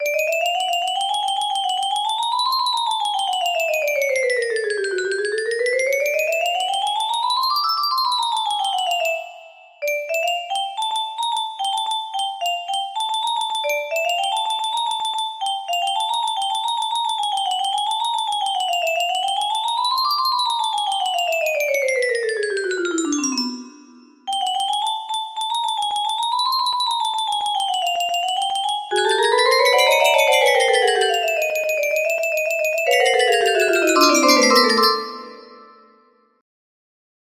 The Swarm Of Wasps music box melody